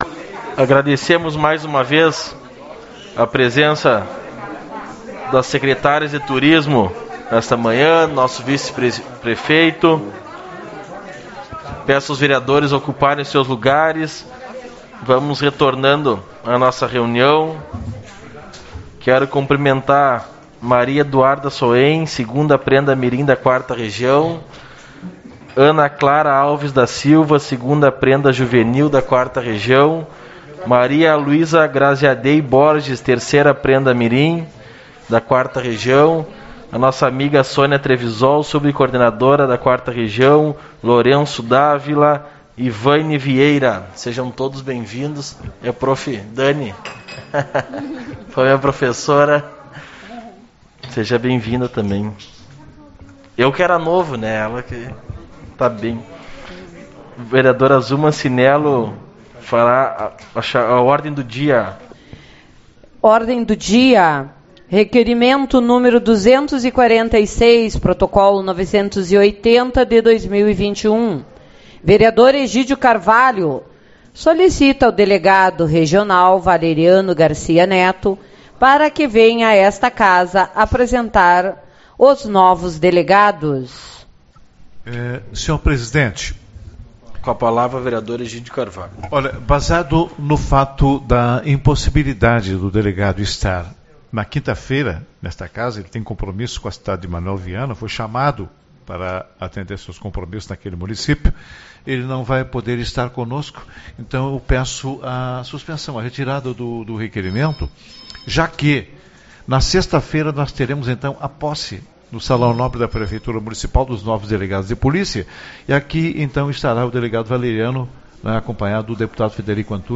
21/09 - Reunião Ordinária